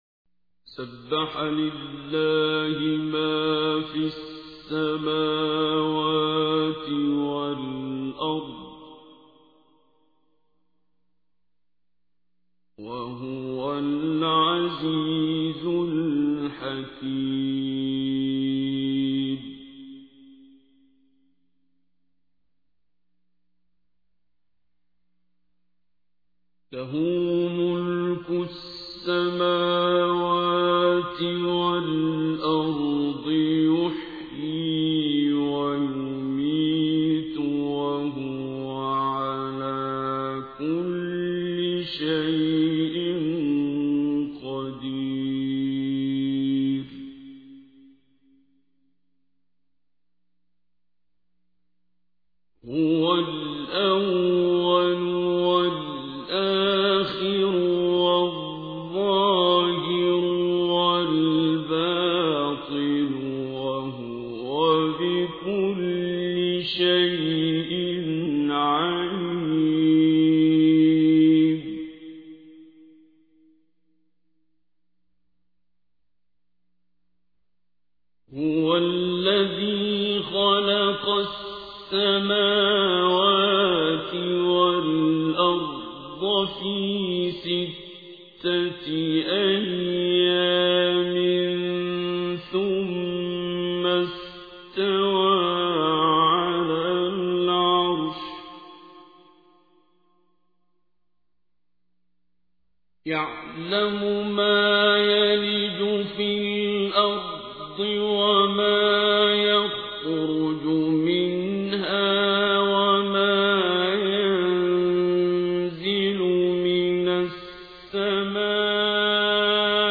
تحميل : 57. سورة الحديد / القارئ عبد الباسط عبد الصمد / القرآن الكريم / موقع يا حسين